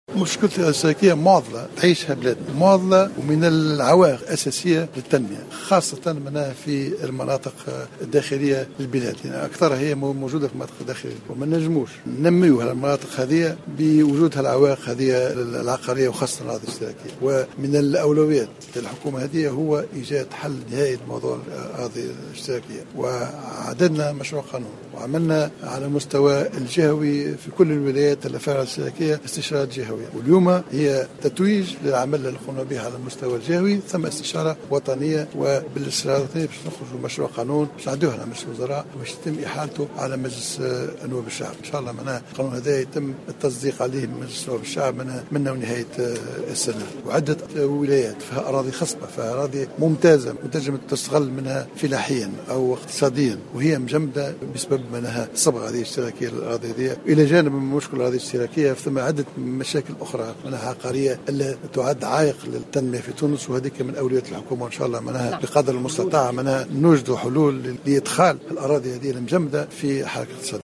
أكد رئيس الحكومة الحبيب الصيد في تصريح لمراسل الجوهرة اف ام صباح اليوم الثلاثاء...